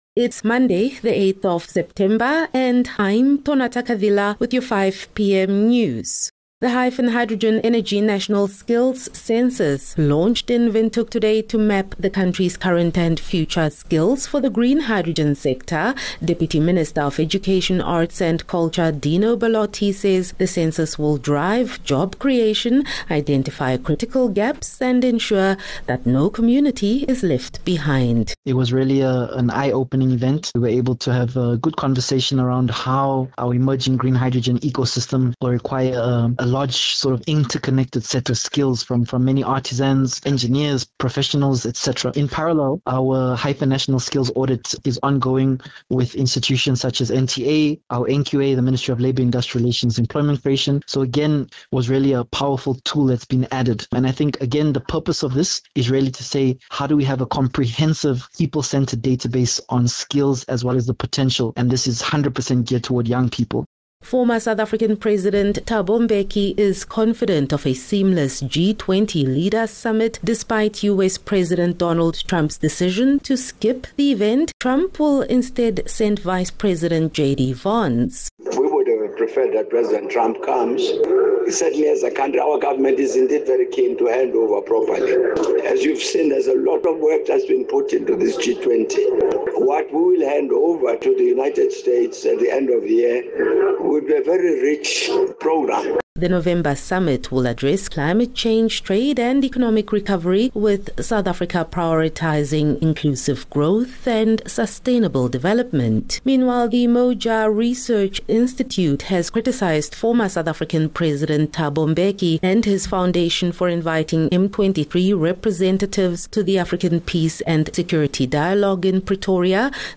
8 Sep 8 September - 5 pm news